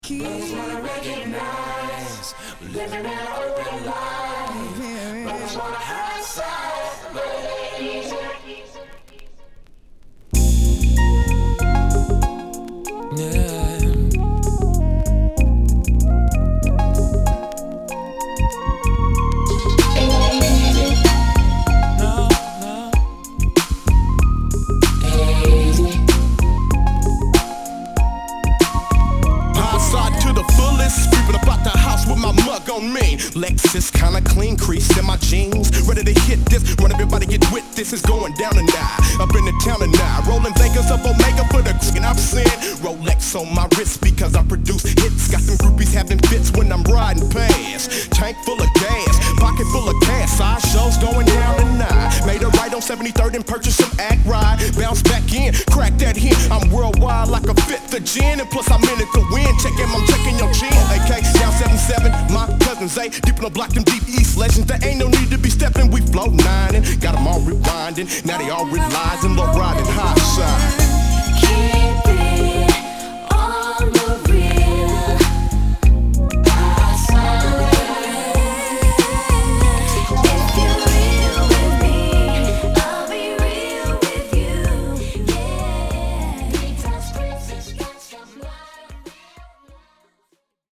・ HIP HOP G-RAP 12' & LP